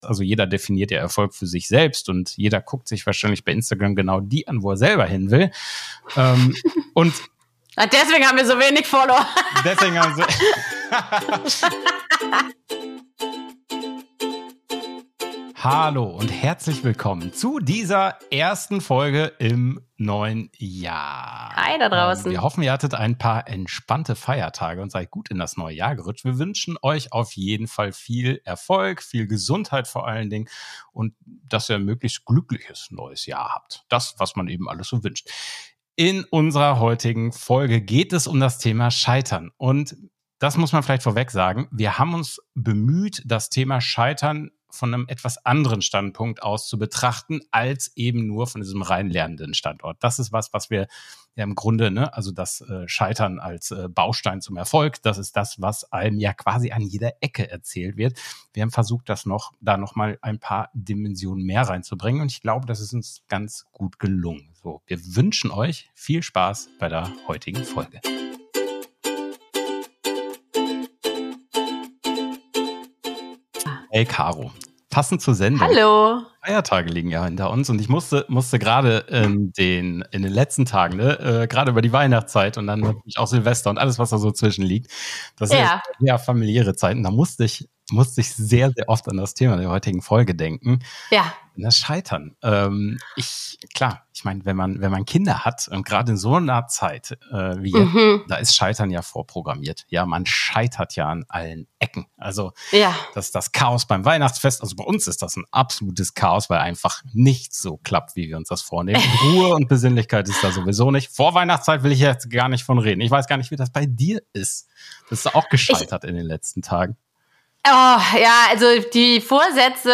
Eine humorvolle und tiefgründige Diskussion darüber, wie Scheitern uns prägt, welche Chancen es bietet und warum es der Weg zum Erfolg ist.